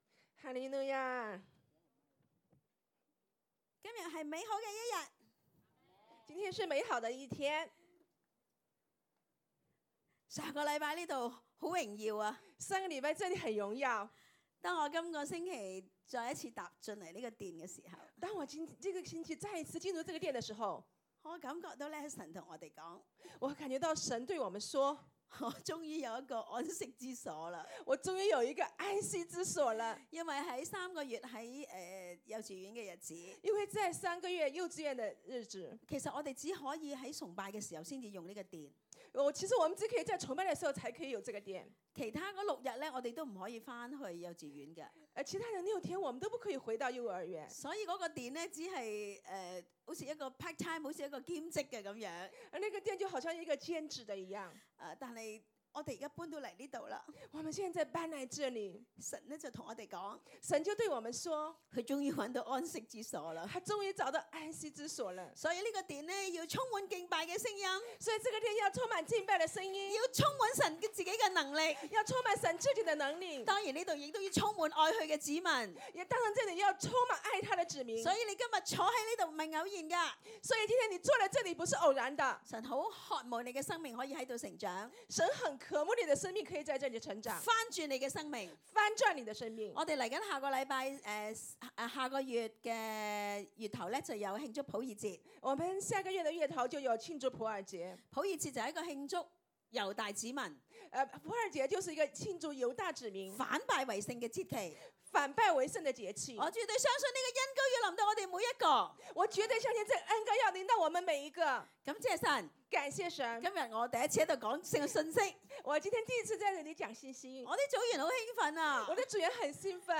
11/02/2018 講道